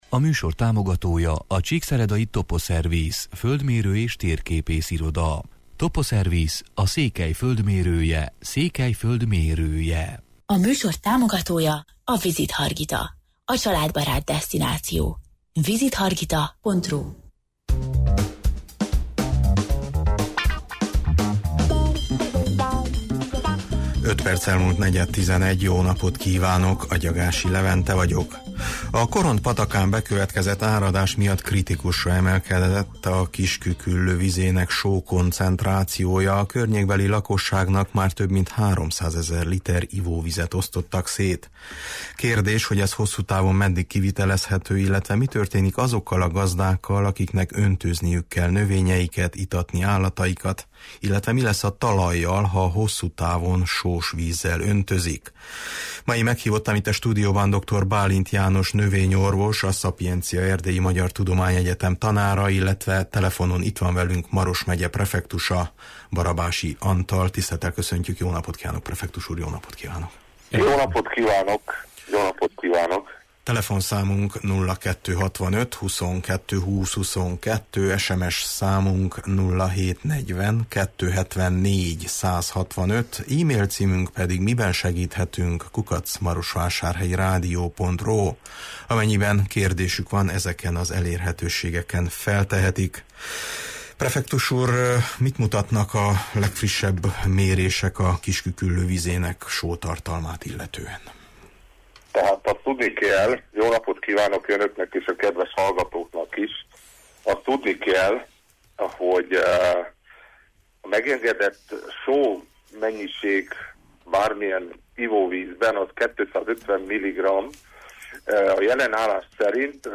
illetve telefonon itt van velünk Maros megye prefektusa, Barabási Antal: